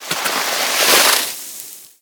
Sfx_creature_snowstalkerbaby_standup_01.ogg